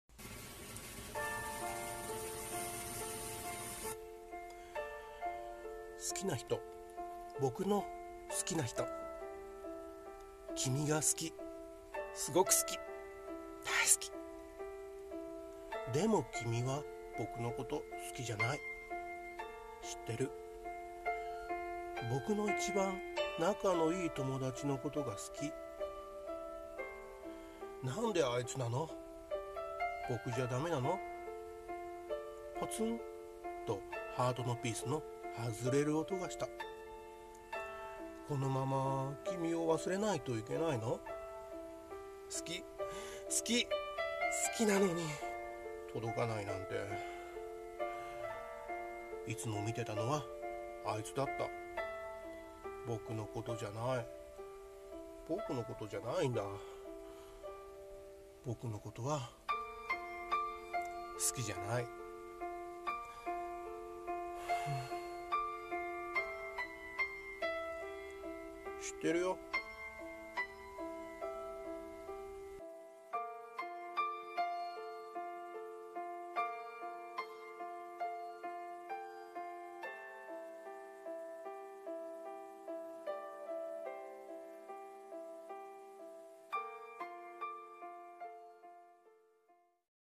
〜1人〜声劇 ｢好きな人。｣